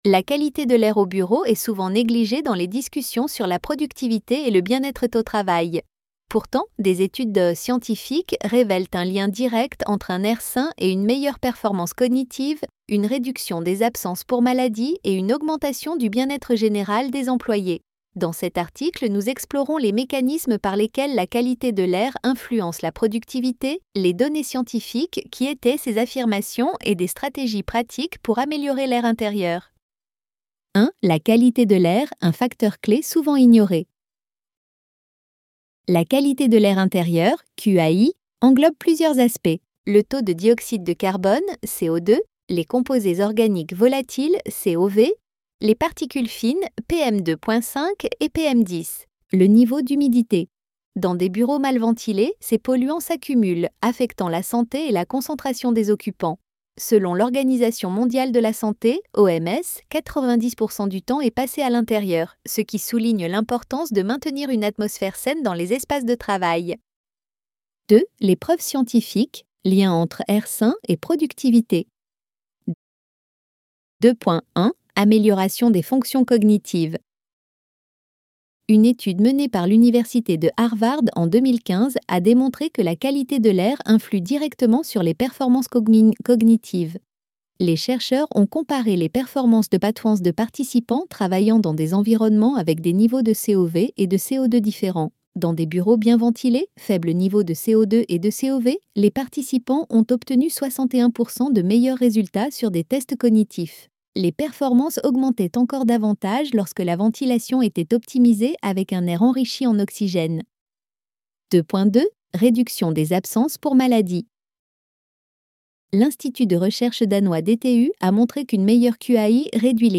ElevenLabs_Chapter_1-18.mp3